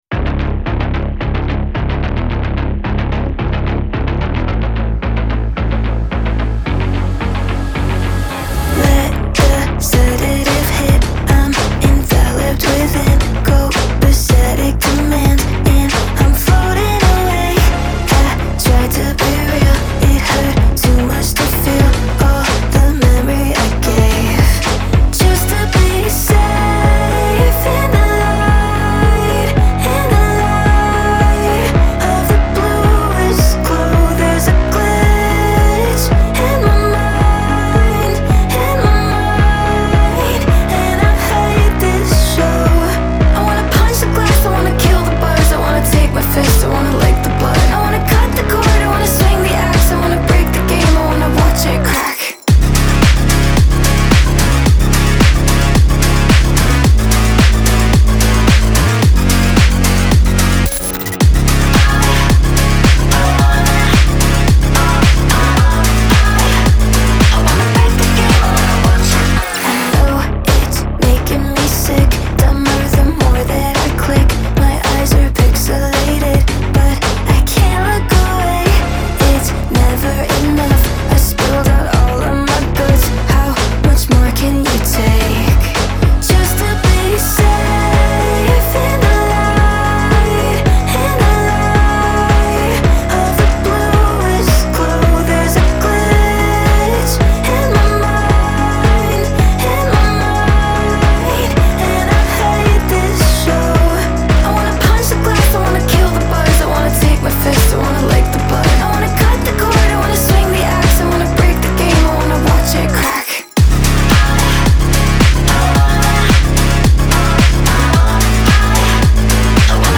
BPM110-110
Audio QualityPerfect (High Quality)
Electropop song for StepMania, ITGmania, Project Outfox
Full Length Song (not arcade length cut)